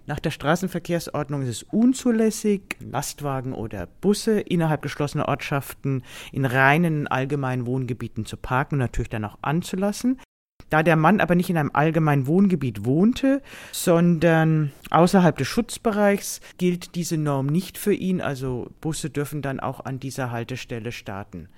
O-Ton + Magazin: Muss man nächtliche Ruhestörung durch startende Schulbusse hinnehmen?